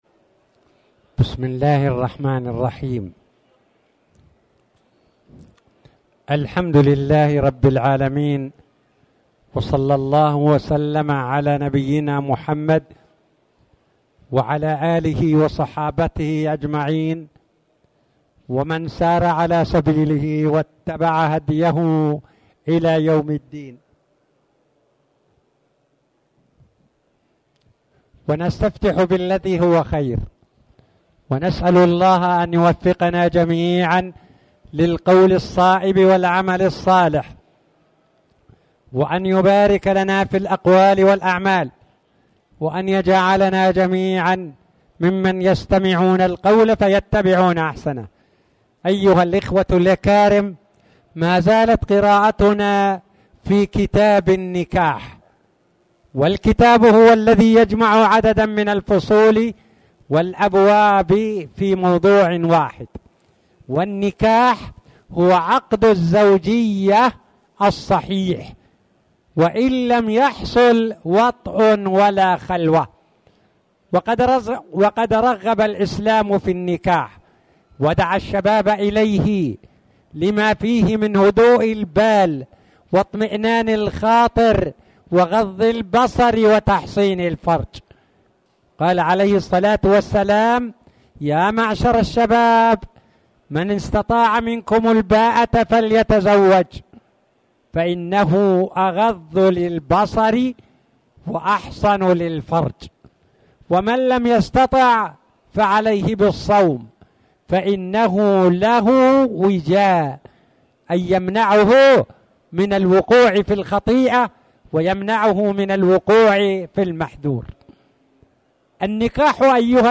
تاريخ النشر ١٦ صفر ١٤٣٨ هـ المكان: المسجد الحرام الشيخ